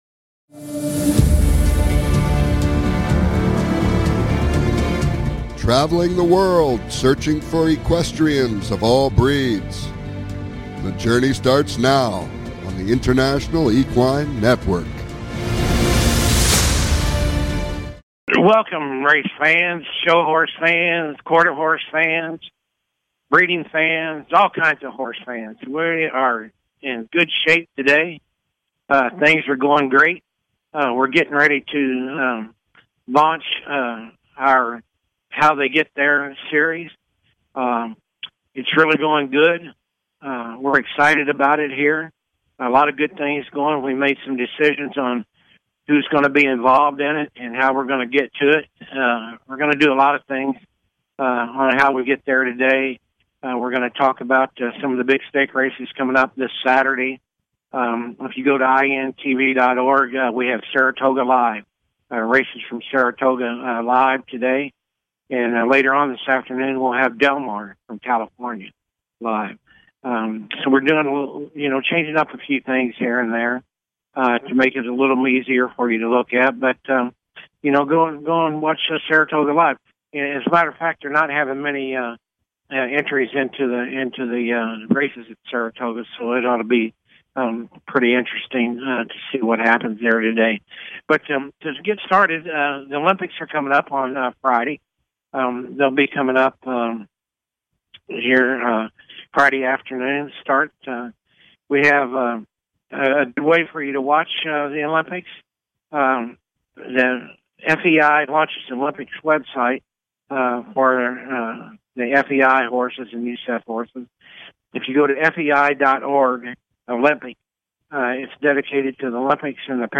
Calls-ins are encouraged!